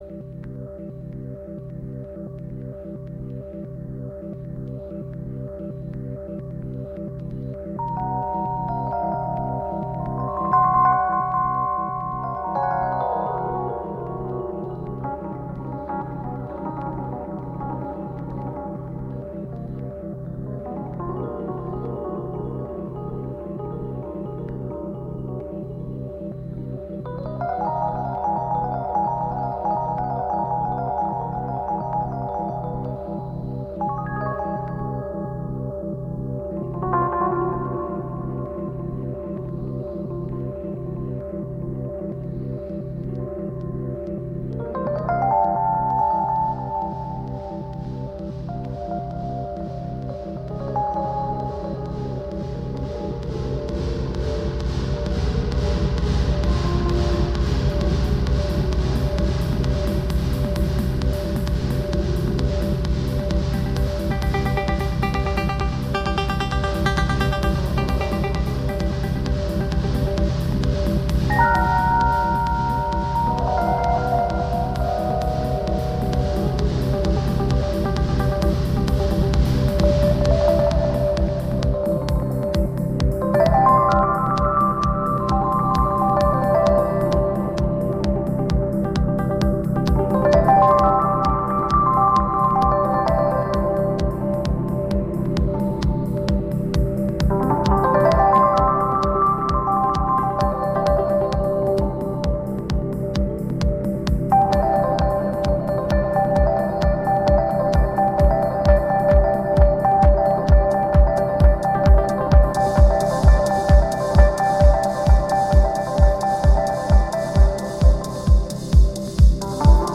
not techno
live in San Francisco, 2008